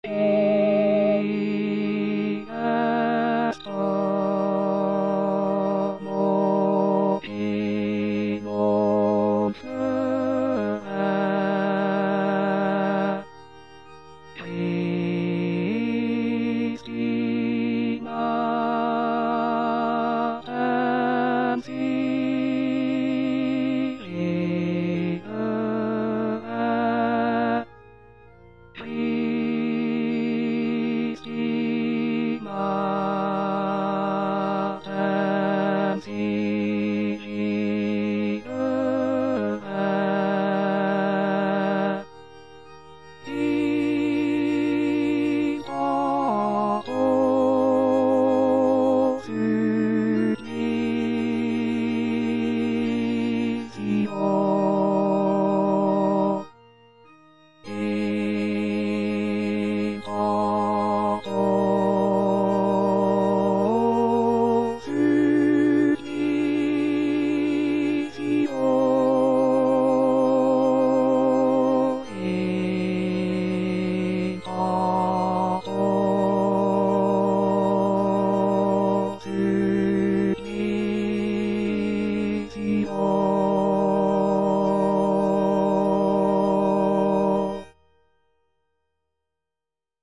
Parole 3: Mulier, ecce filius tuus        Prononciation gallicane (à la française)